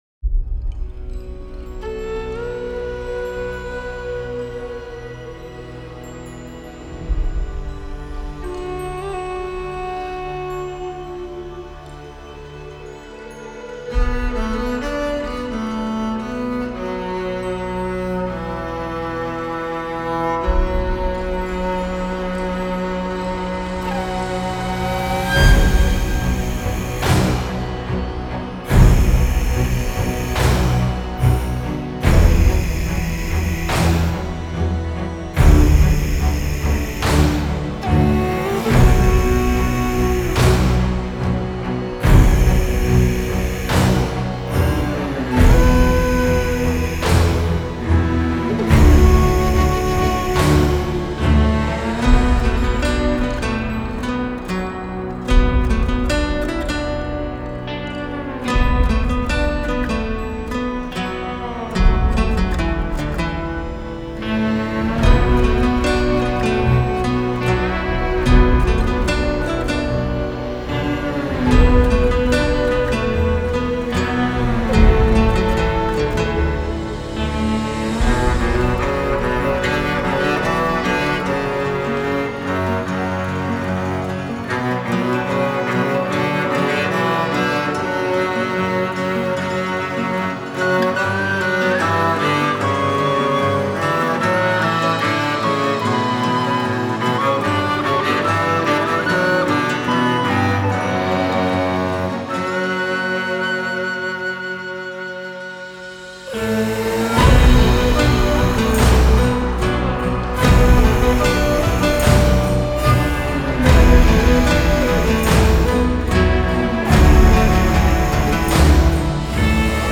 aggressiv
böse
cool
Gitarre
Drums
Woodwinds
repetitiv
ernst
Industrial
spannend
geheimnisvoll
Rock
Rhythmus